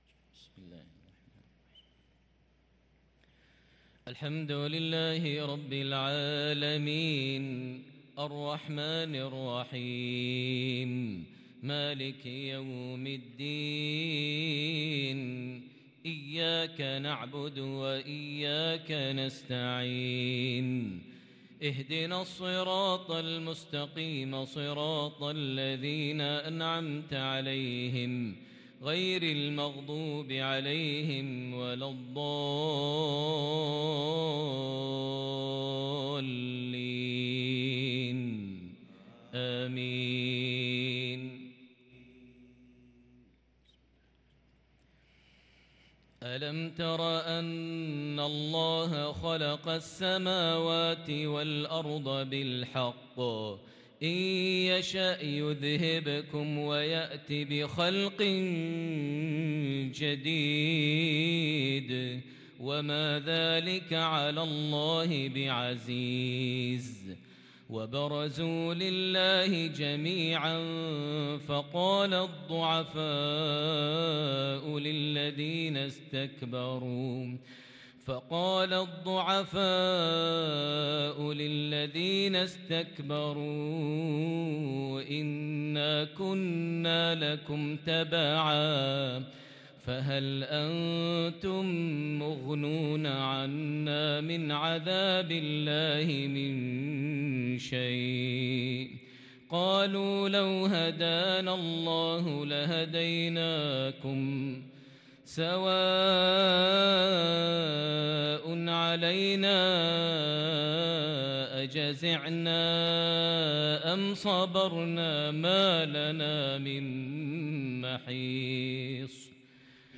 صلاة العشاء للقارئ ماهر المعيقلي 9 صفر 1444 هـ